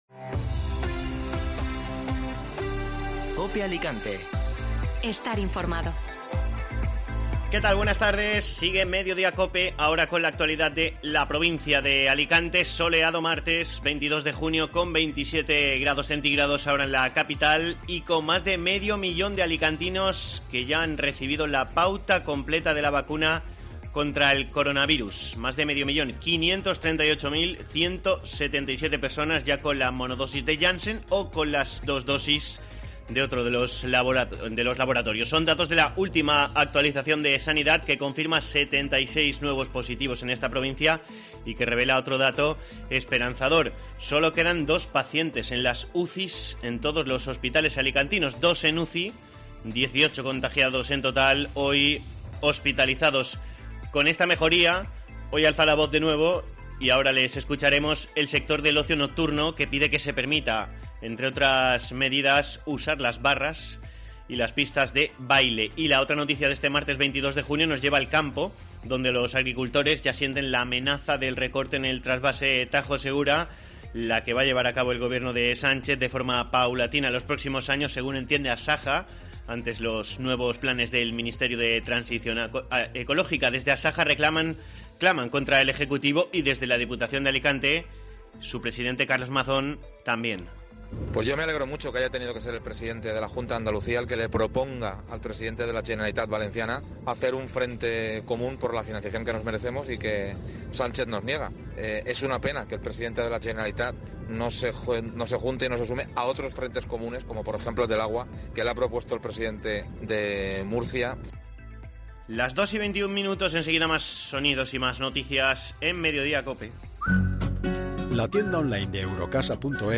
Informativo Mediodía COPE (Martes 22 de junio)